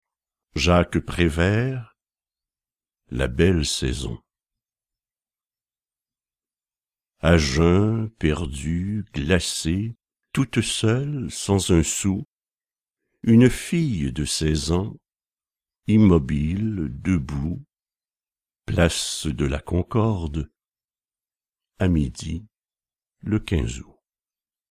/wp-content/uploads/2012/01/bellesaison.mp3 dit par Serge REGGIANI Jacques PRÉVERT (Paroles, 1945) (poèmes tout courts)